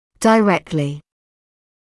[dɪ’rektlɪ], [də-], [daɪ-][ди’рэктли]прямо, напрямую